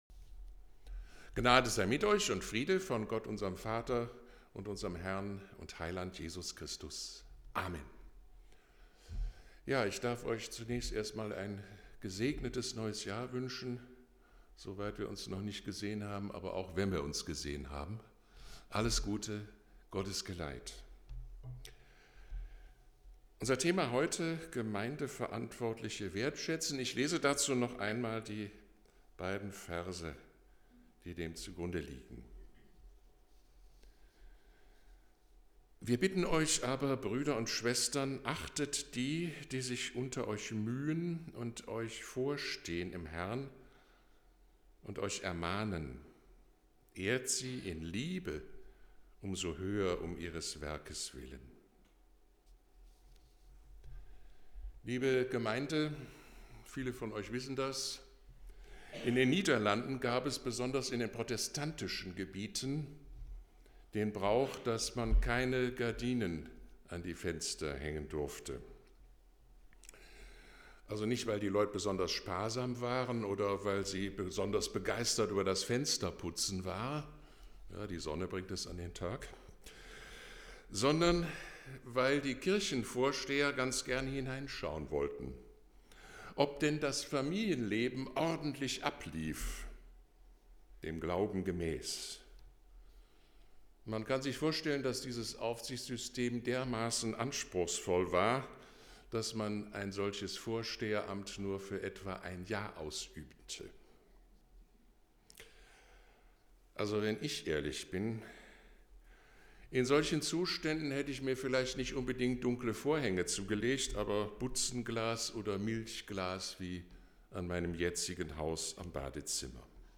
Gottesdienst | Ev.